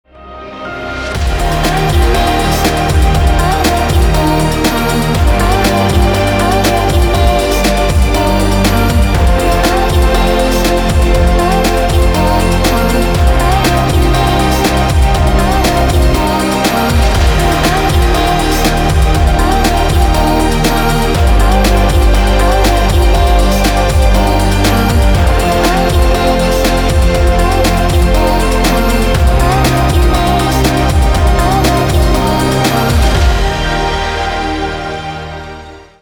• Качество: 320, Stereo
электронная музыка
спокойные
Electronica
расслабляющие
chillwave
Indietronica
Расслабляющая электронная музыка